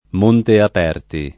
vai all'elenco alfabetico delle voci ingrandisci il carattere 100% rimpicciolisci il carattere stampa invia tramite posta elettronica codividi su Facebook Monteaperti [ monteap $ rti ], Monte Aperti [ id. ], Monte Aperto [ m 1 nte ap $ rto ] → Montaperti